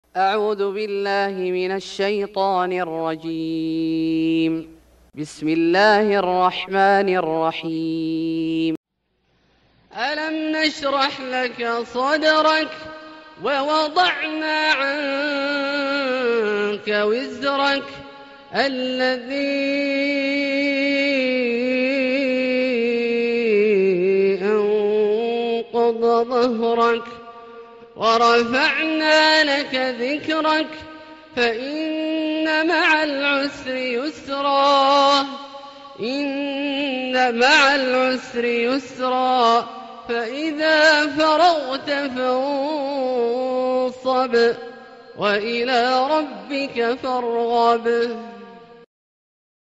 سورة الشرح Surat Ash-Sharh > مصحف الشيخ عبدالله الجهني من الحرم المكي > المصحف - تلاوات الحرمين